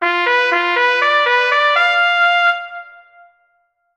Bugle Call